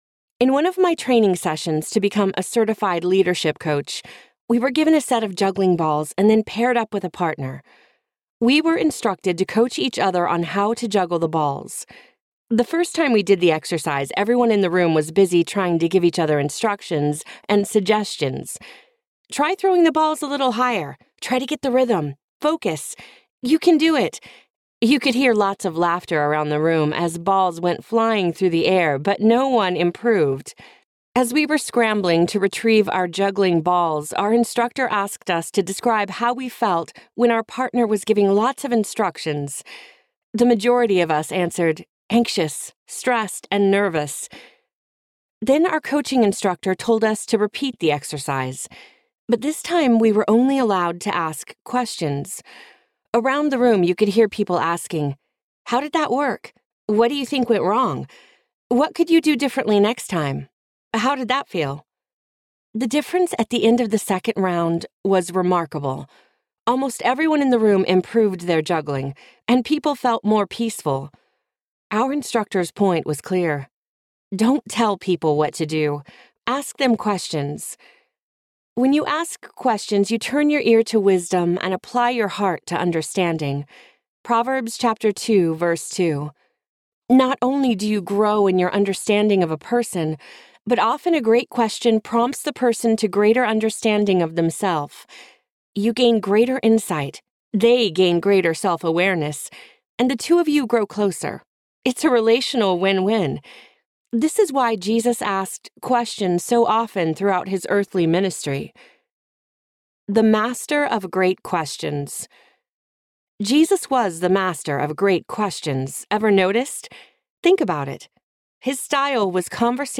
How to Listen So People Will Talk Audiobook
Narrator
4.98 Hrs. – Unabridged